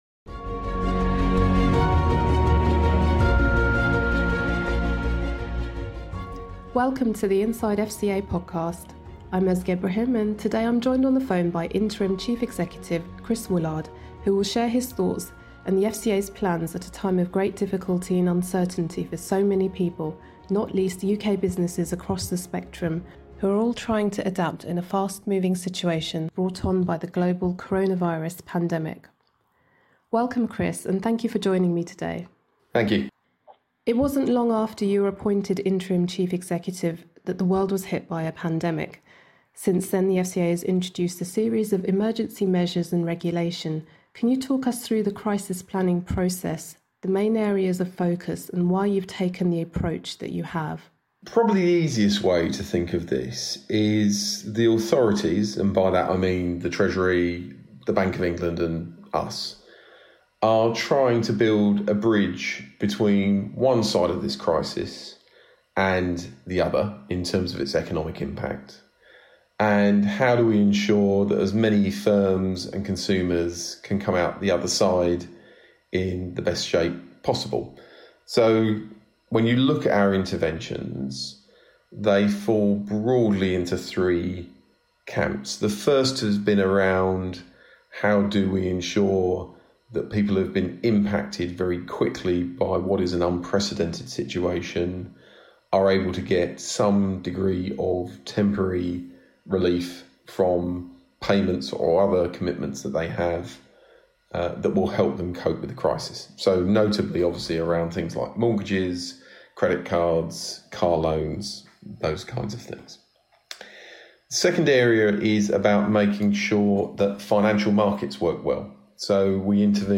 Listen to the Inside FCA Podcast interview with Interim Chief Executive Chris Woolard as he discusses both pandemic and future planning.